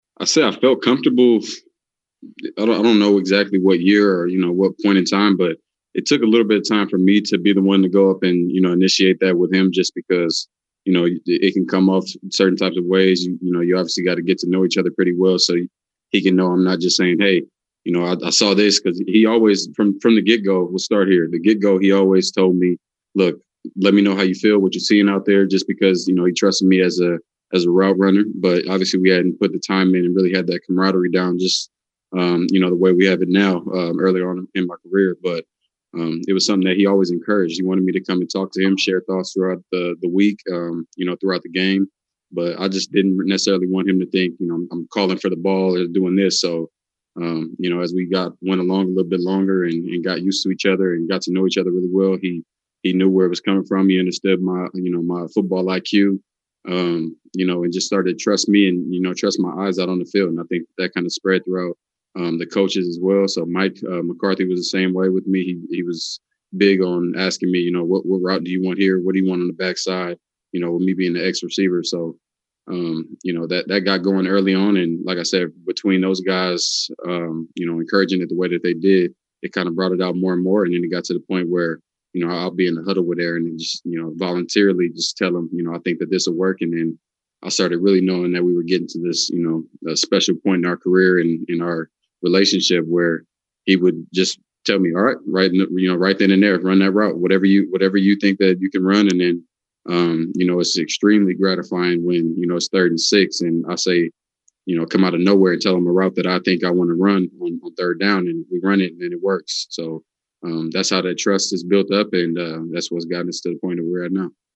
Both players met reporters today to talk about how their relationship has evolved first off the field, and then spectacularly, on the field in recent years.